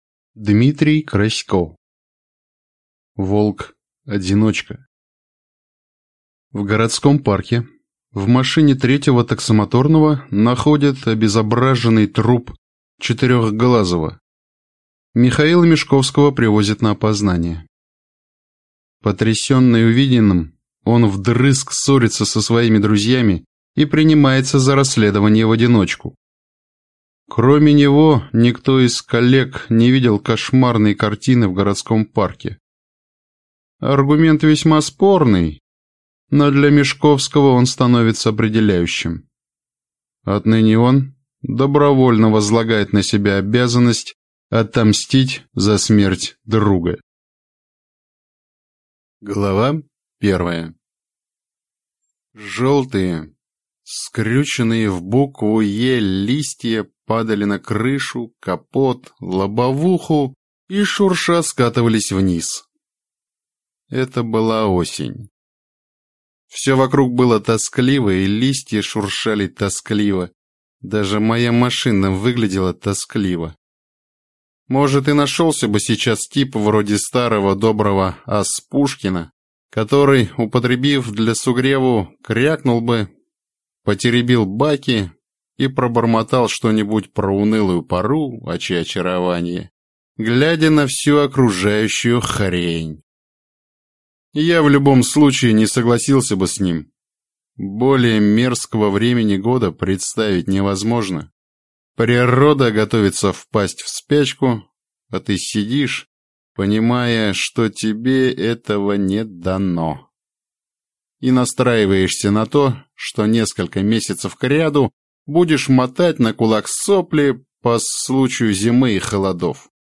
Аудиокнига Волк-одиночка | Библиотека аудиокниг